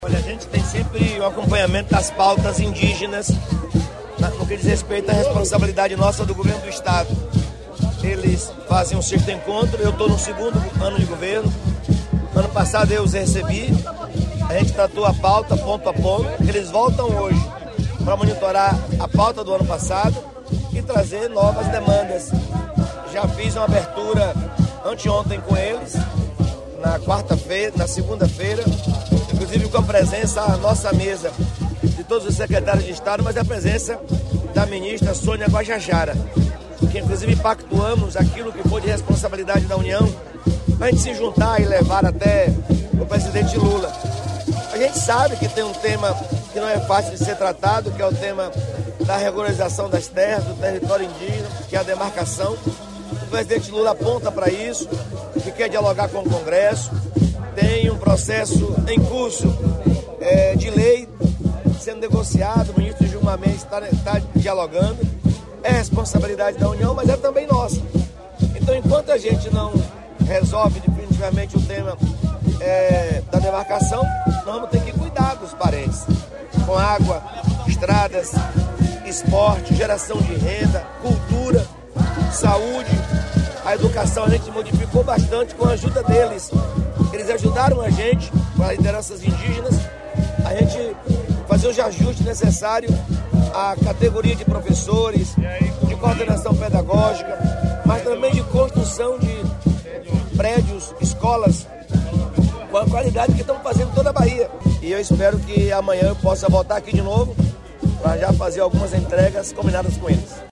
🎙Jerônimo Rodrigues – Governador da Bahia